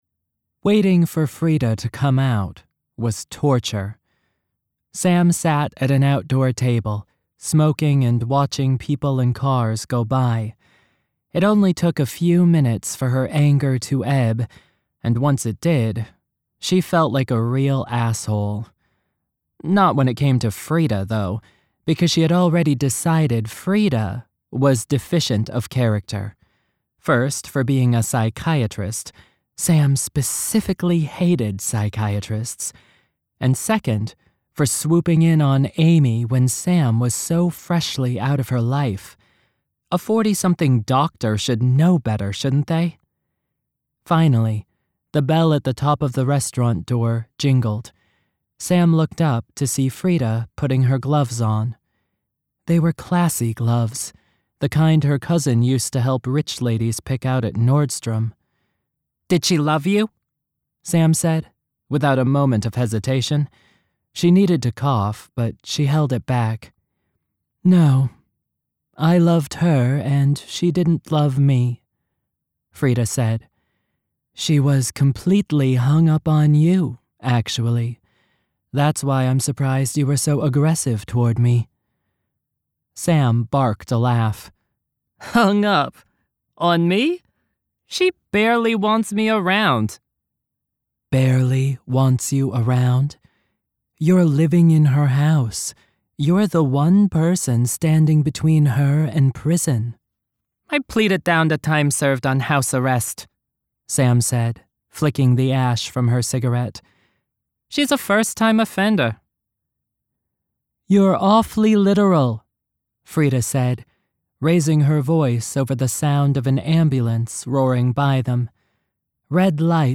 Narration Samples
3rd Person
Two women + New York dialect